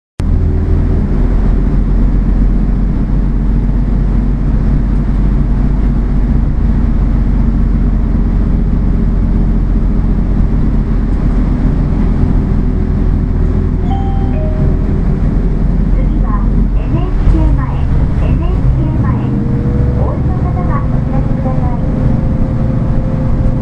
音声合成装置  クラリオン(ディスプレイ)
車内放送ｱﾅｳﾝｻｰ  １名
車内放送  ドアが閉まる際「発車します御注意ください」と流れます
降車ボタンを押した際「次ぎ止まります」と流れます。